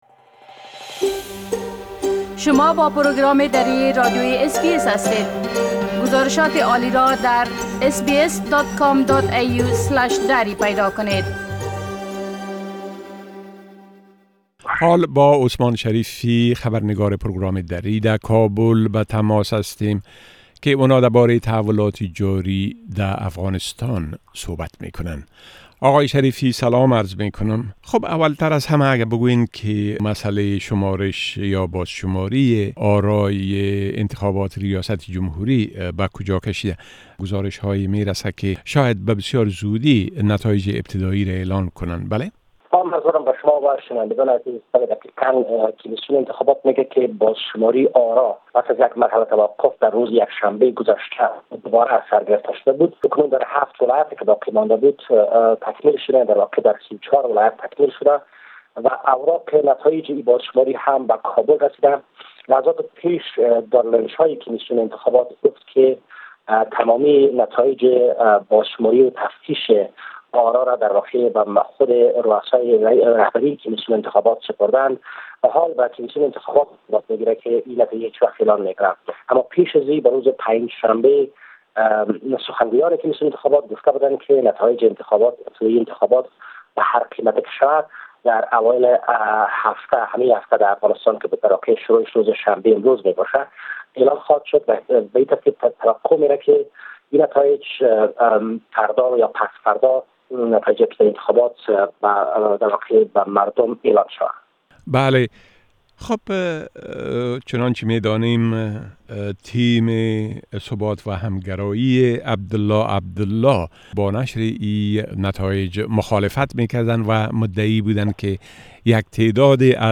گرازش كامل خبرنگار ما در كابل بشمول وضعيت امنيتى و تحولات مهم ديگر در افغانستان را در اينجا شنيده ميتوانيد.